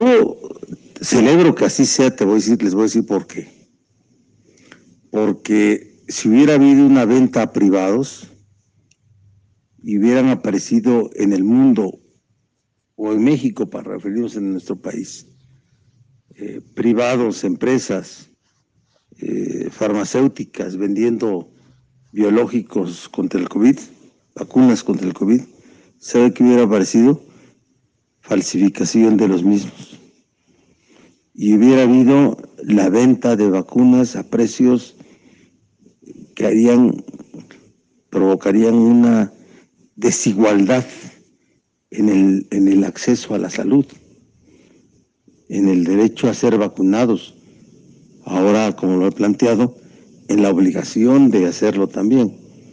En su habitual conferencia de prensa, agregó que en este momento los precios ya serían inalcanzables para algunos sectores de la sociedad, además de la aparición de vacunas falsas.